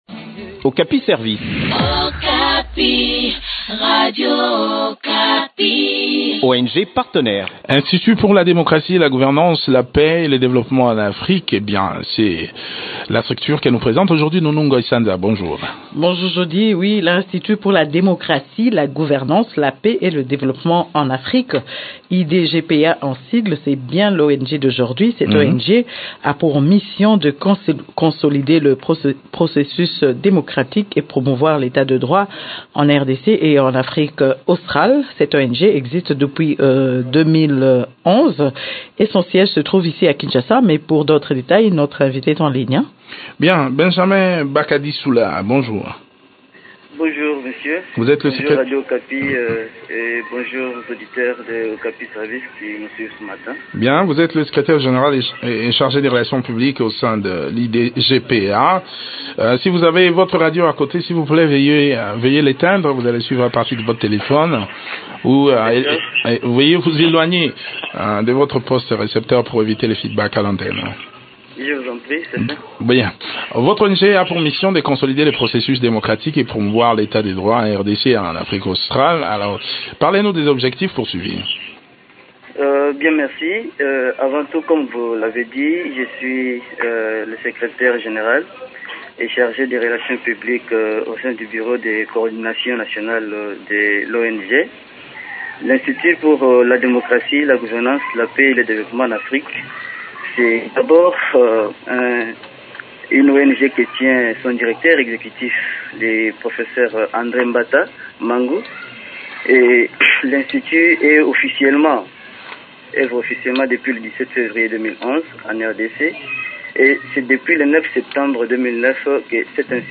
Le point des activités de cette ONG dans cet entretien